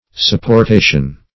Search Result for " supportation" : The Collaborative International Dictionary of English v.0.48: Supportation \Sup`por*ta"tion\, n. Maintenance; support.